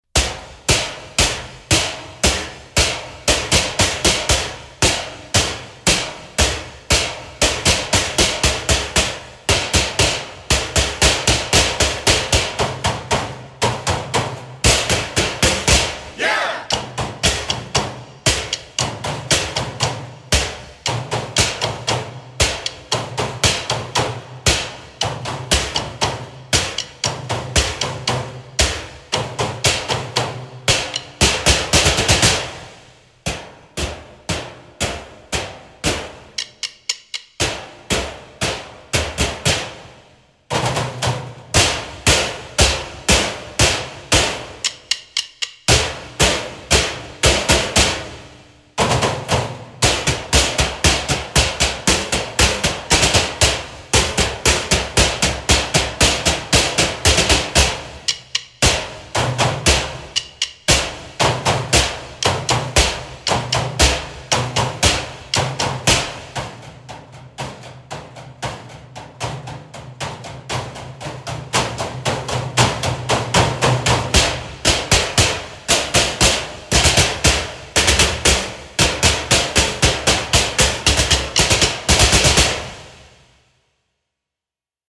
Voicing: Percussion Choir